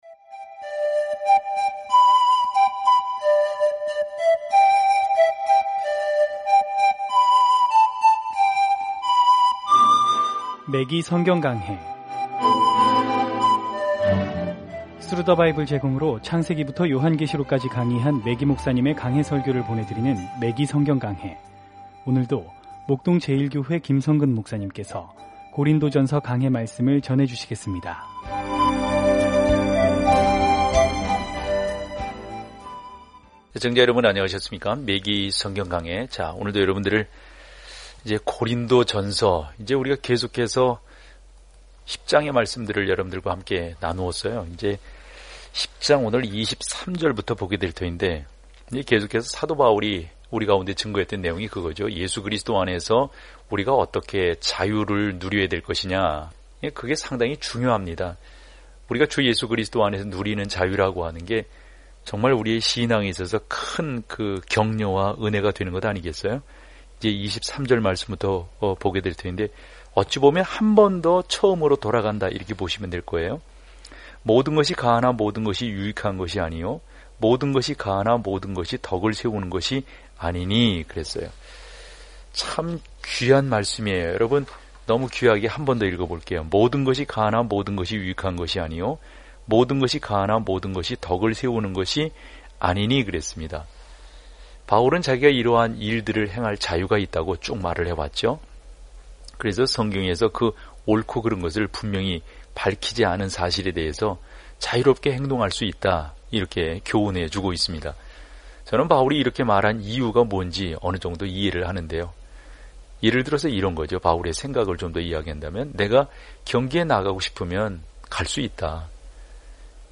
고린도인들에게 보낸 첫째 편지에서 다루는 주제는 젊은 그리스도인들이 직면하고 있는 문제들에 대한 실질적인 관심과 시정을 제시하는 것입니다. 오디오 공부를 듣고 하나님의 말씀에서 선택한 구절을 읽으면서 매일 고린도전서를 여행하세요.